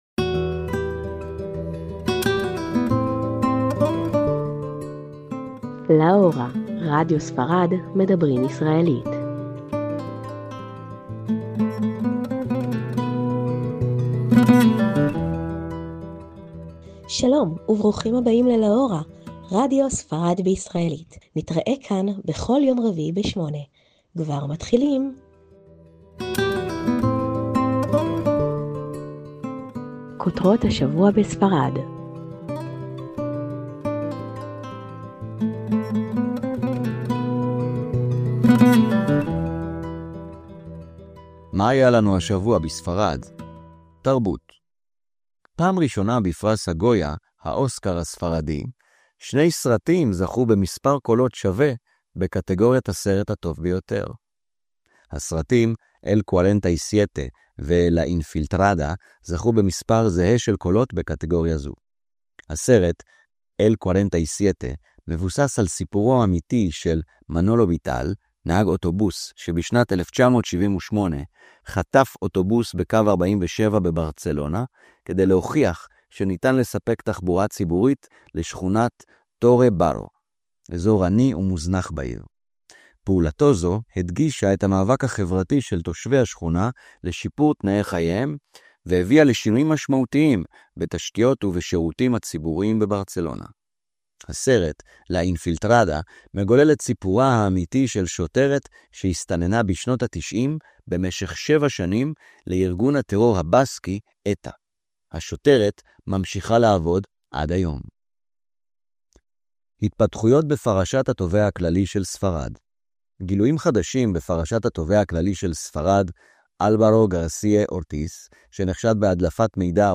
מיזם רדיו לטובת הישראלים בספרד